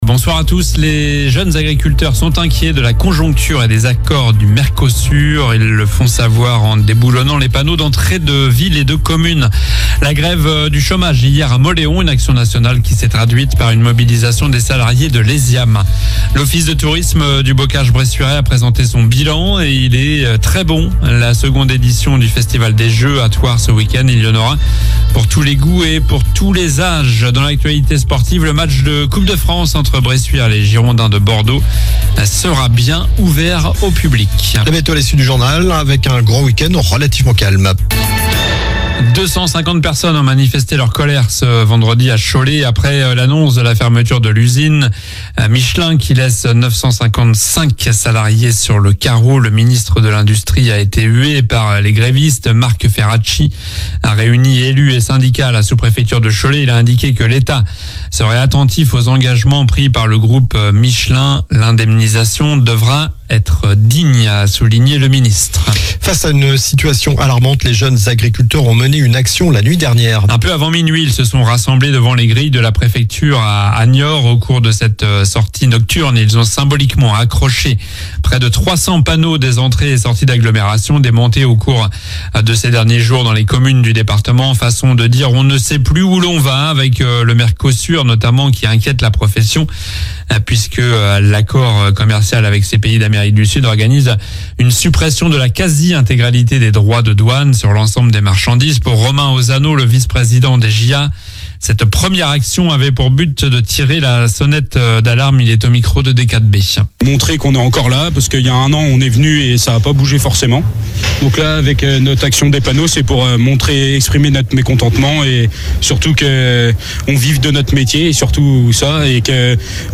Journal du vendredi 08 novembre (soir)